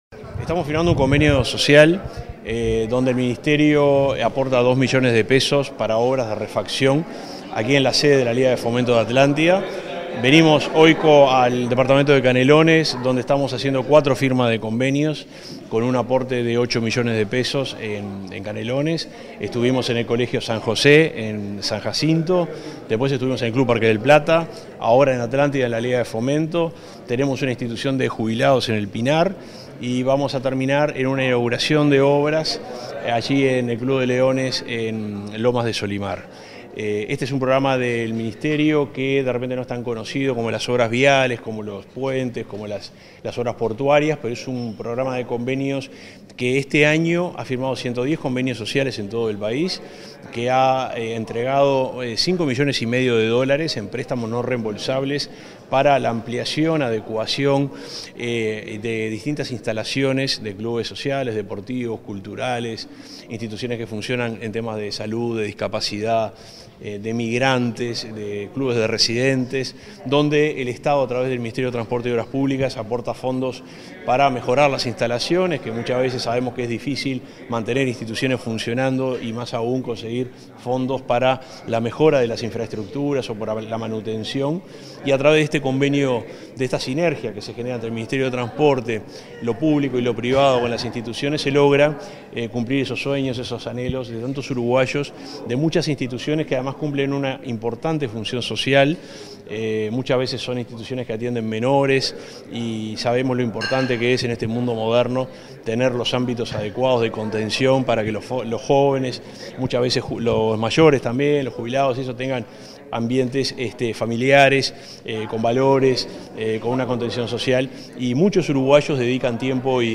Declaraciones del subsecretario de Transporte y Obras Públicas, Juan José Olaizola
Declaraciones del subsecretario de Transporte y Obras Públicas, Juan José Olaizola 14/12/2023 Compartir Facebook X Copiar enlace WhatsApp LinkedIn Tras la firma de convenios con instituciones sociales en el departamento de Canelones, este 14 de diciembre, el subsecretario, Juan José Olaizola, dialogó con la prensa.